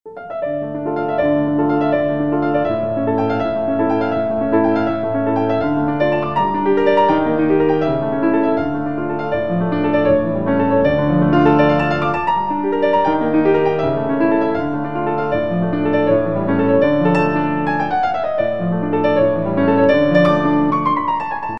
Категория: Классические | Дата: 09.12.2012|